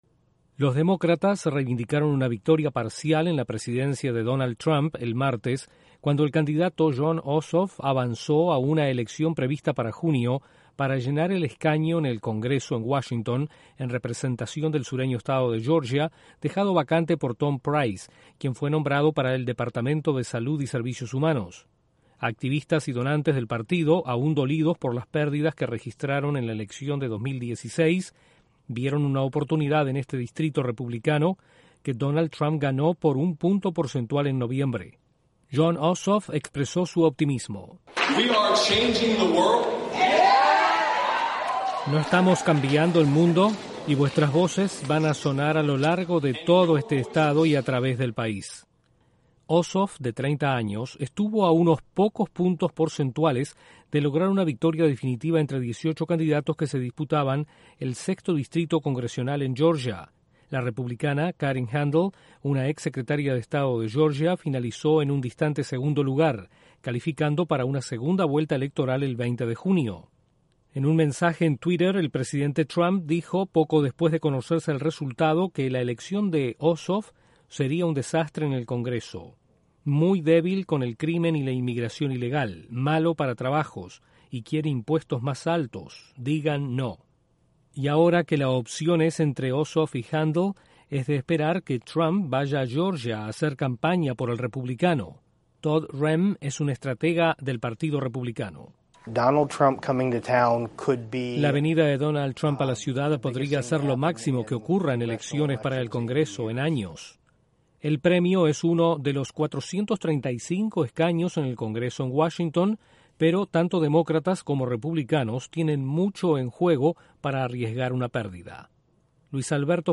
Demócratas avanzan en una elección especial en Georgia, en lo que es visto como un desafío a la presidencia de Donald Trump. Desde la Voz de América en Washington informa